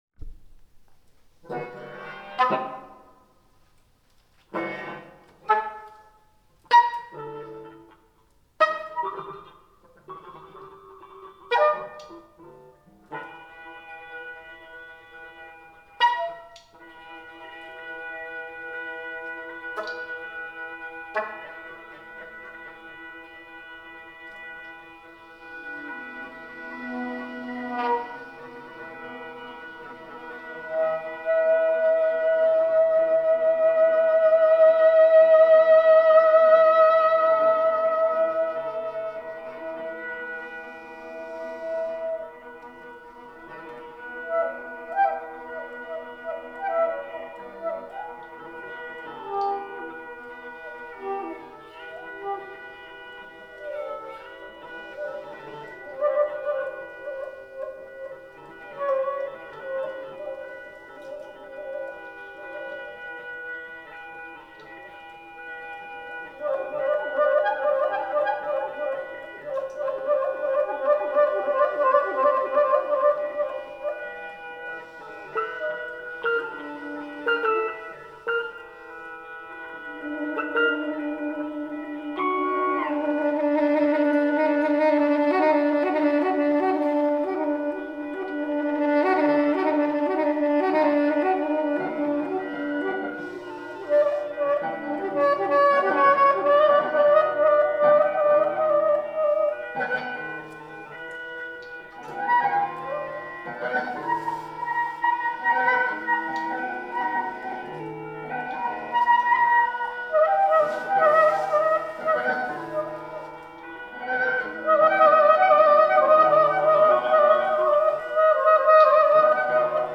Vor ein paar Tagen machten wir (wir könnten uns Cool Brothers nennen) mal wieder eine Session im Duo mit Gitarre, Saxofon, Flöte, allerlei Effekten (loops & freezes) – auch ein Schlagzeug war im Raum zuhanden. Besonders gefiel mir ein stilles, frei improvisiertes Stück – betiteln möchte ich es gemäss der Quintessenz unseres Zusammenspiels mit